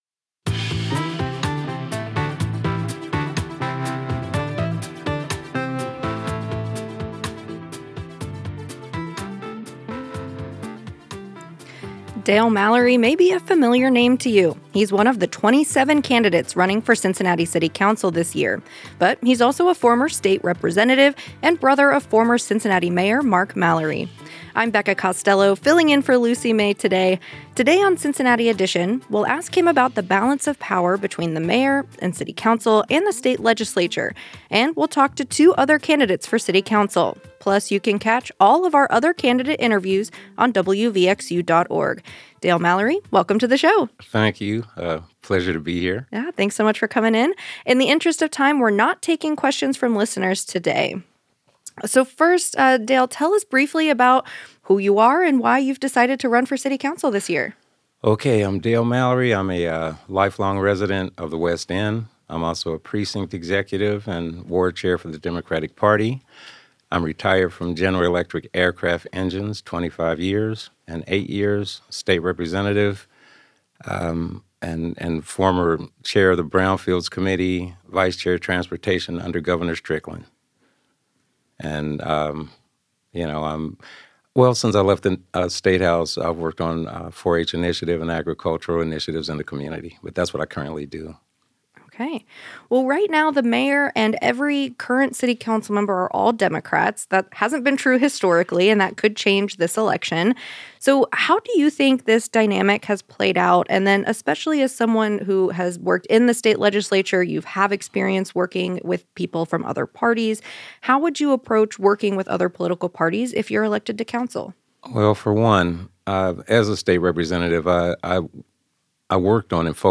Cincinnati Edition has invited all of the Cincinnati City Council candidates for interviews.